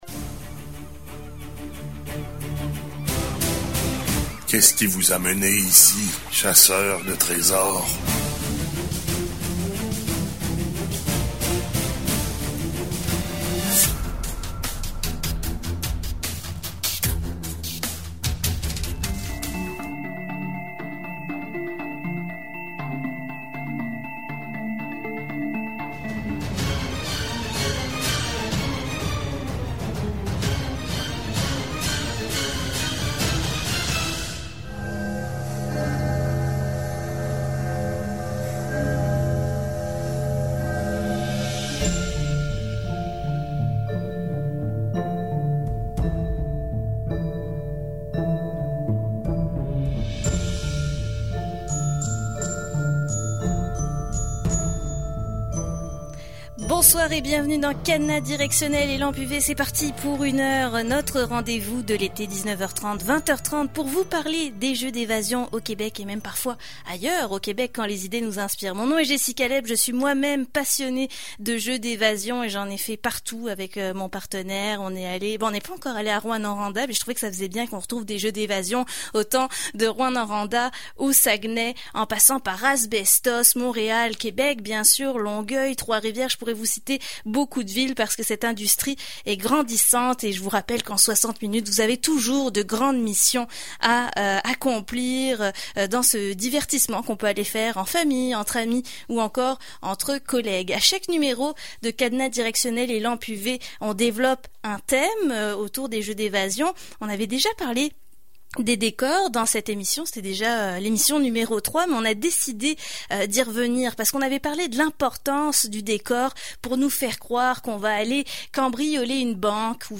Heureusement pour toi, voici la balado de cette émission, consacrée à la conception et la construction des décors dans les jeux d’évasion.